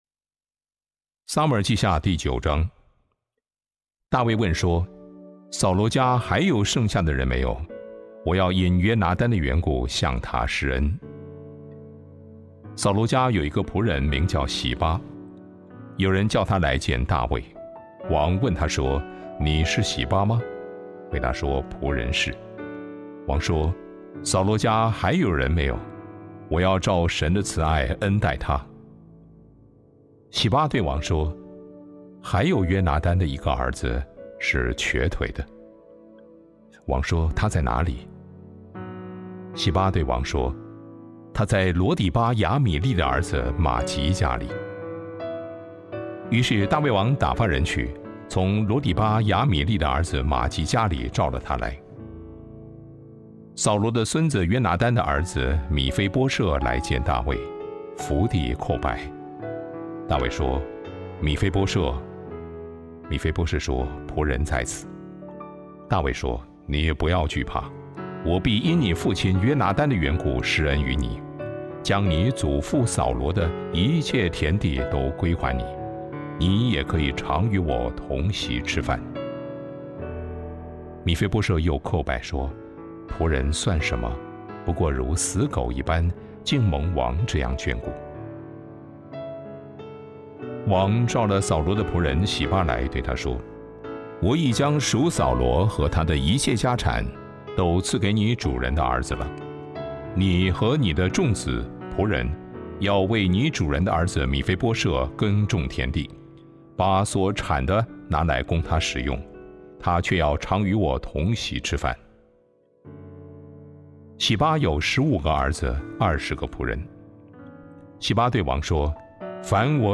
遵守承诺 | Devotional | Thomson Road Baptist Church